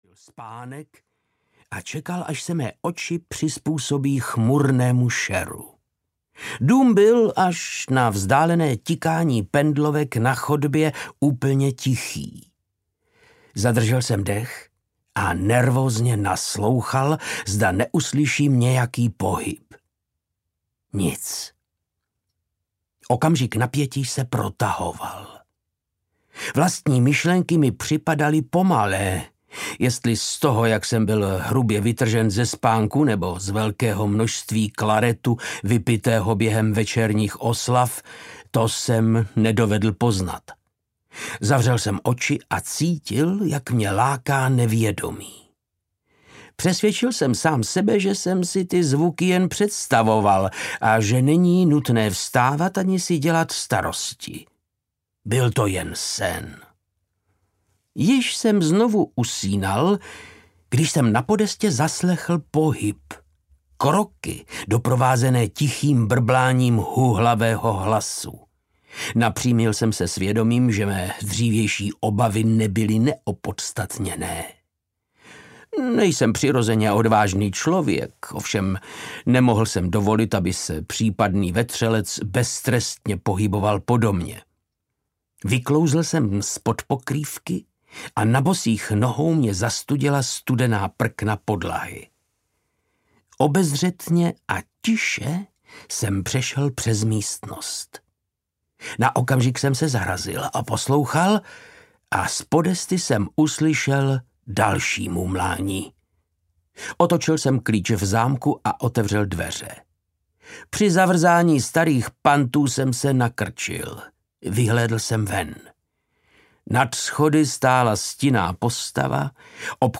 Sherlock Holmes a Ztracená závěť audiokniha
Ukázka z knihy
• InterpretVáclav Knop